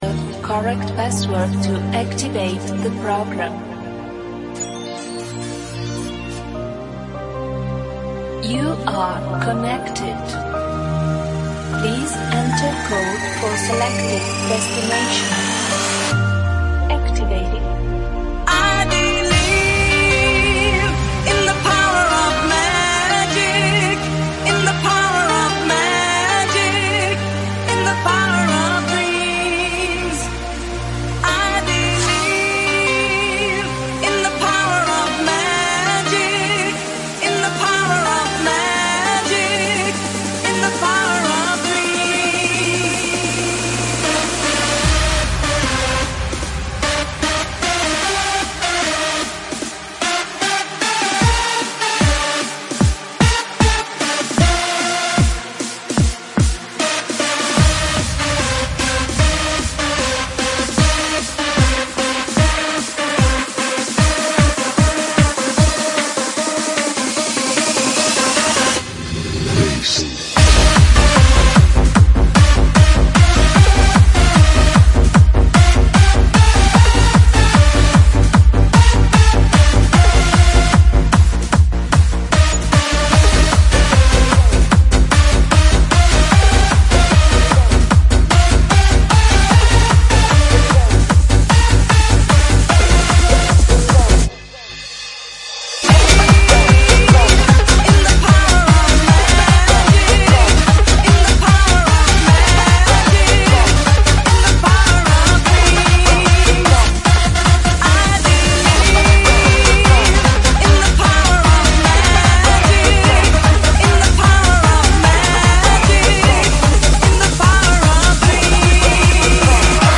CANTADITAS DANCE Y BASES HARDHOUSE